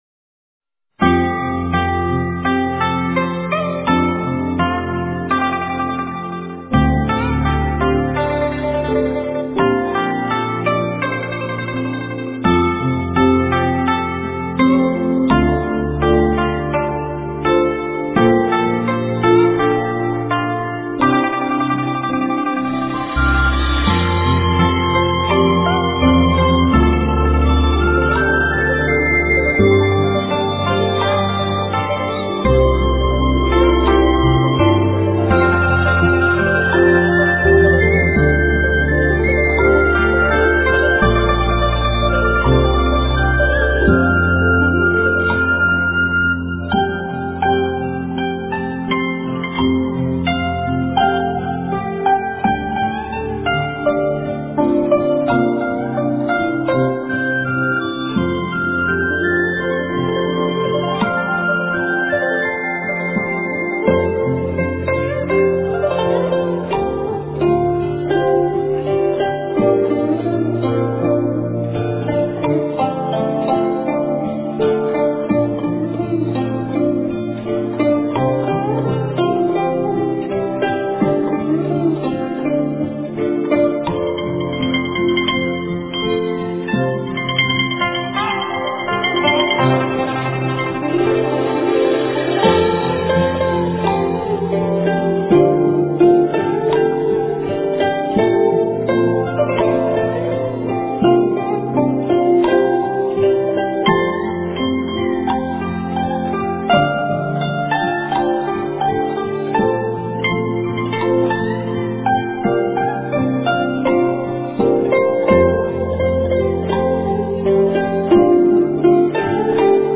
往生咒--古筝
往生咒--古筝 冥想 往生咒--古筝 点我： 标签: 佛音 冥想 佛教音乐 返回列表 上一篇： 水清月现--古筝礼赞 下一篇： 大悲咒--古筝笛子 相关文章 大孔雀明王心咒--十八一心童声合唱团 大孔雀明王心咒--十八一心童声合唱团...